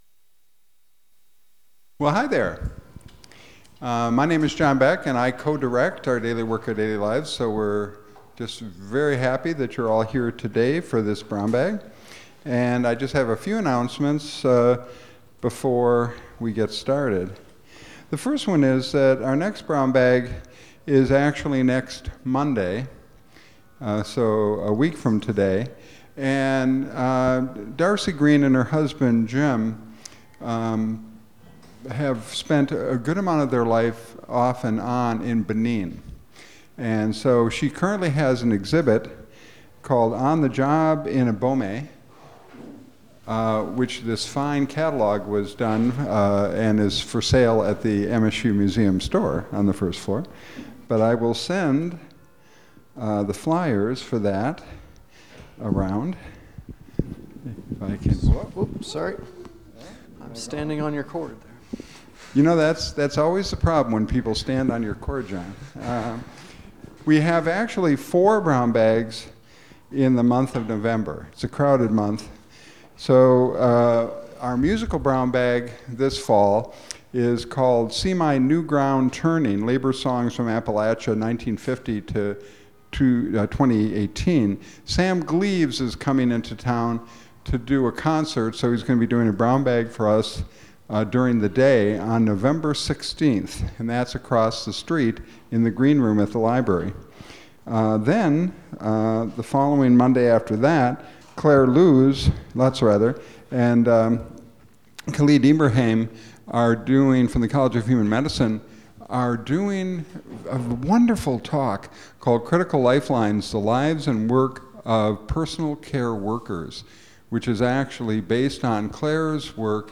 He describes the lives of several Lesotho individuals who witnessed Lesotho independence and how their activism and goals for the future of Lesotho changed over time. He answers questions from the audience.